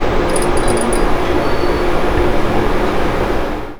Sound file 1.6 The sound of lights turned on in the cell